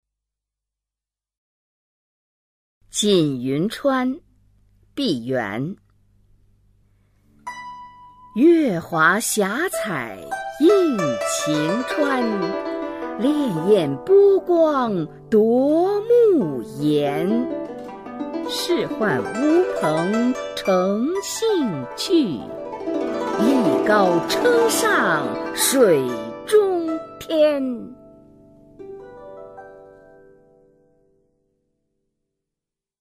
[清代诗词诵读]毕沅-锦云川 配乐诗朗诵